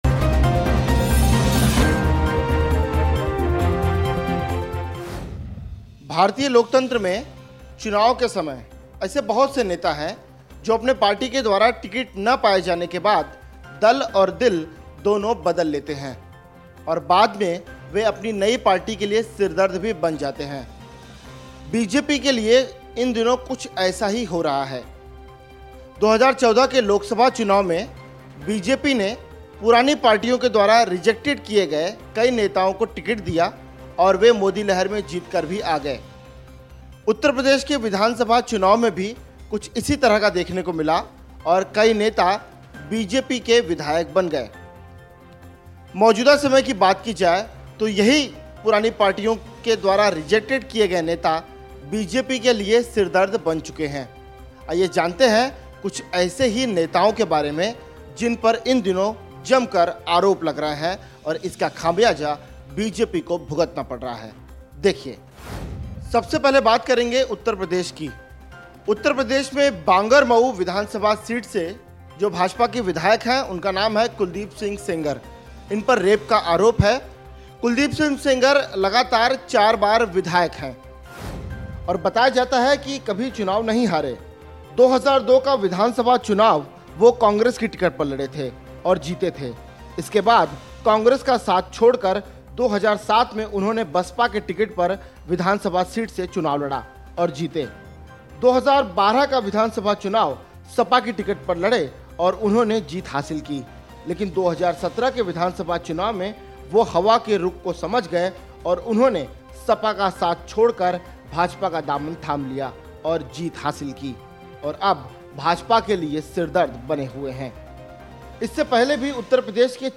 News Report / चुनाव देख दुसरें दलों से शामिल हुए नेता, अब बीजेपी को शर्मिंदा कर रहें हैं!